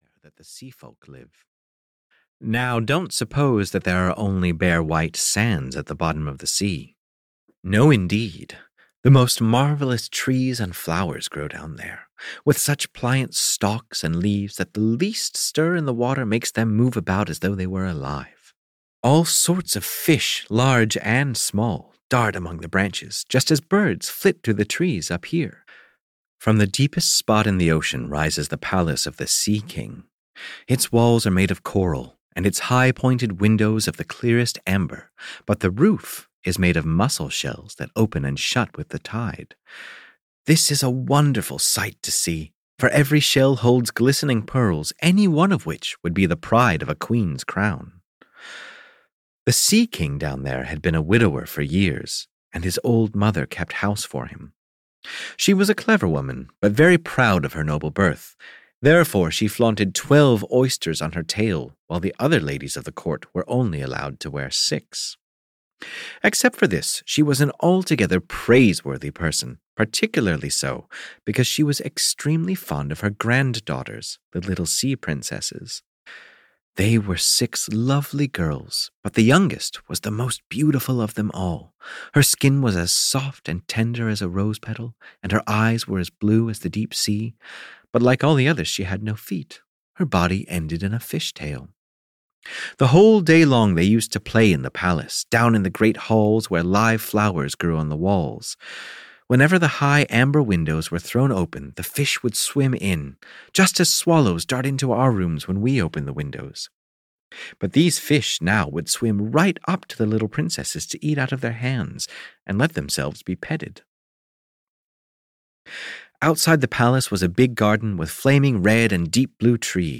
The Little Mermaid (EN) audiokniha
Ukázka z knihy